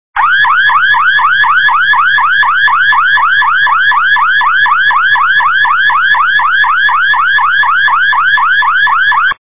/32kbps) 16kbps (18.4кб) Описание: Сирена ID 24943 Просмотрен 7021 раз Скачан 2138 раз Скопируй ссылку и скачай Fget-ом в течение 1-2 дней!